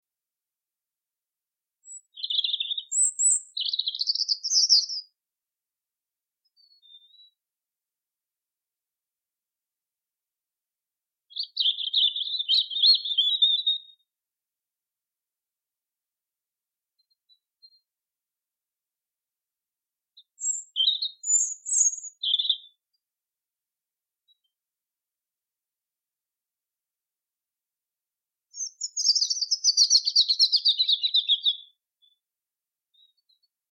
Nämä kaikki kolme lajia ovat keväällä helppoja havaita tyypillisistä äänistään. Kuusitiainen Hippiäinen Punarinta Kuuntele kuusitiainen: Kuuntele kuusitiainen ja hippiäinen: Kuuntele punarinta: Jaa: Linkki kopioitu leikepöydälle
punarinta.mp3